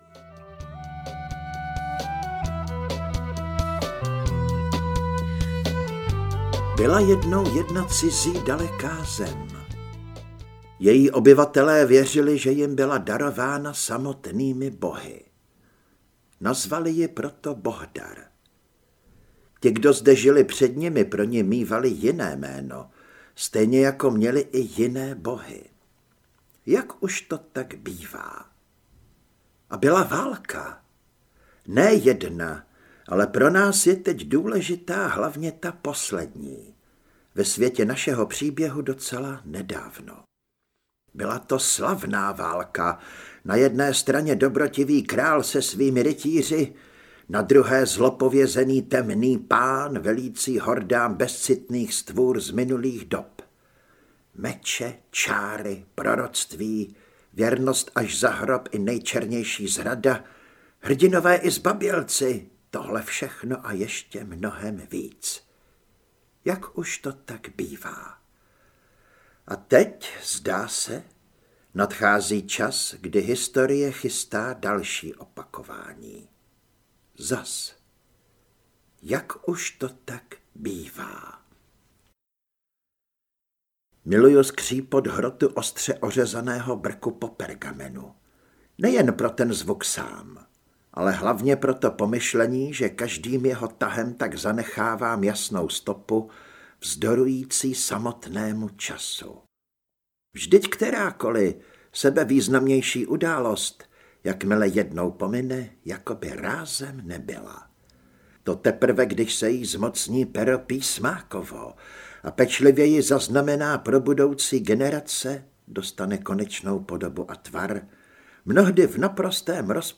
Modrá luna čarodějů, rudý měsíc války audiokniha
Ukázka z knihy
modra-luna-carodeju-rudy-mesic-valky-audiokniha